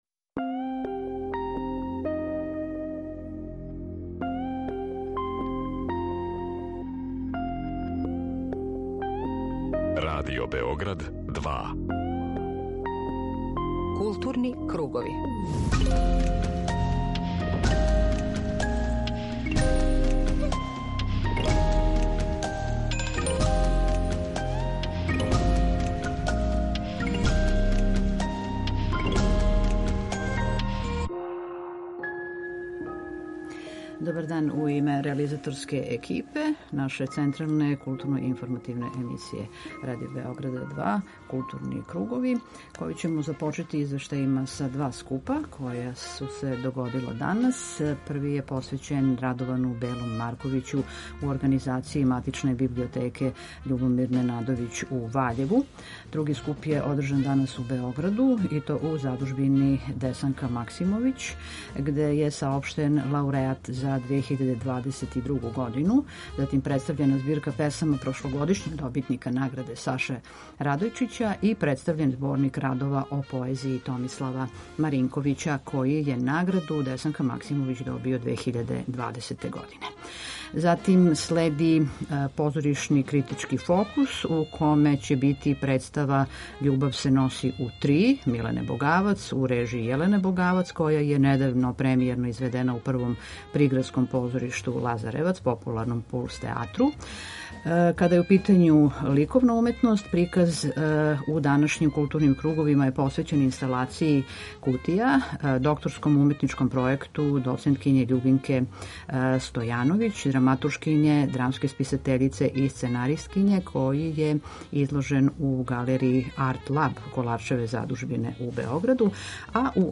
У данашњој централној културно-информативној емисији Радио Београда 2, чућемо извештаје са два скупа: